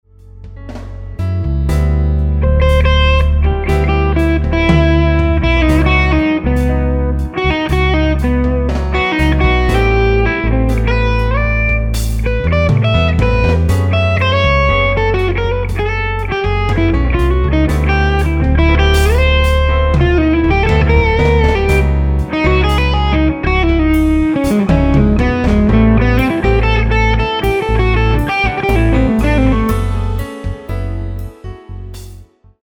This is a 30 second test clip where I miced the Hermida Thiele port 1X12 by placing the ribbon back about 2 1/2 feet.
No EQ at all, no delay, just a touch of 2.5sec reverb in mix.
There is a certain clarity that I think I'm hearing that is very nice.
This clip has the LC sound, very nice.
Very pleasing fat sound.
For lack of a better term - very "direct" sounding.
HermidaRoyerR121Test.mp3